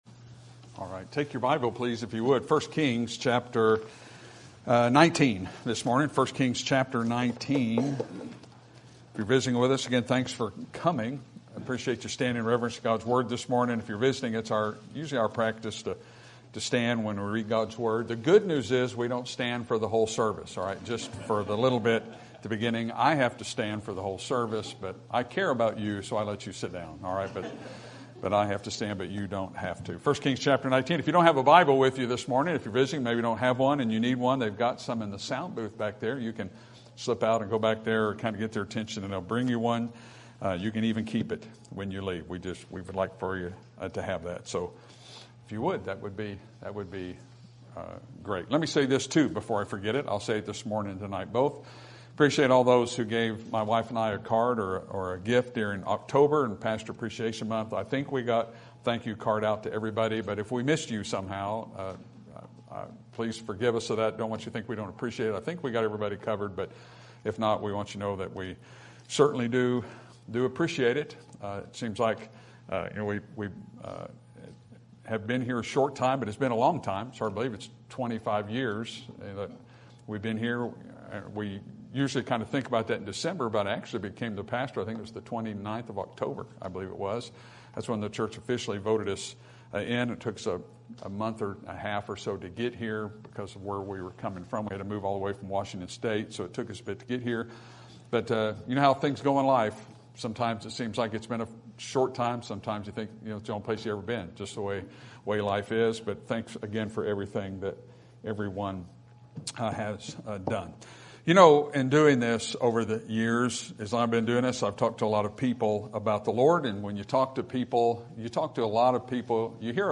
Sermon Topic: Men Who Encountered God Sermon Type: Series Sermon Audio: Sermon download: Download (25.16 MB) Sermon Tags: 1 Kings Elijah Ahab Jezebel